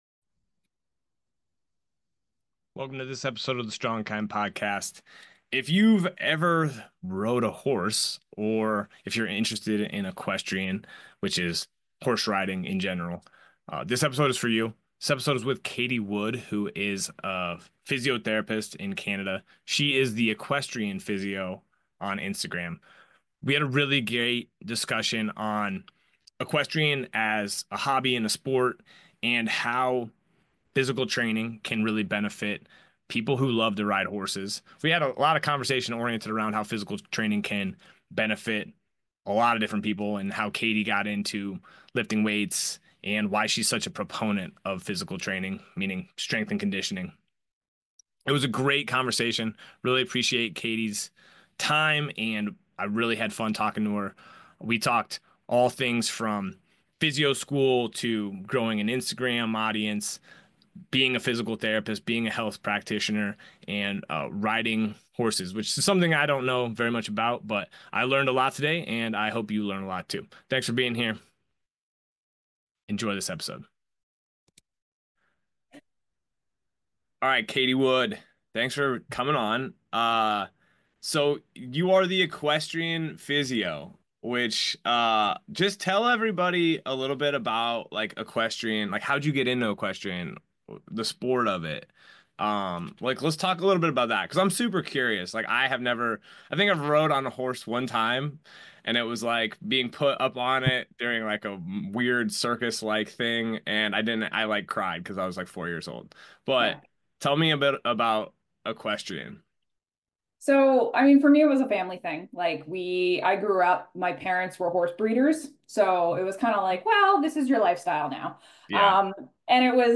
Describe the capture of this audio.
It was a great chat, and I hope you enjoy it.